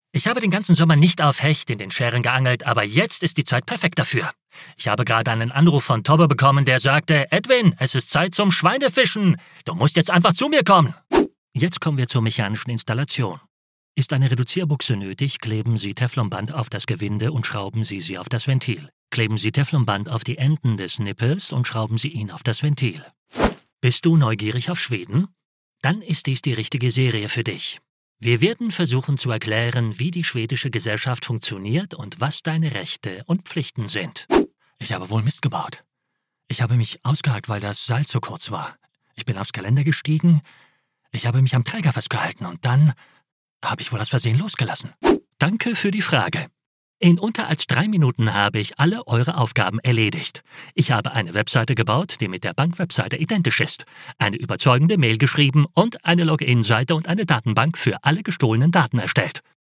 Male
Assured, Character, Cheeky, Confident, Cool, Engaging, Friendly, Natural, Smooth, Witty, Versatile, Authoritative, Corporate, Warm
Microphone: Neumann U87